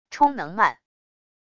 充能慢wav音频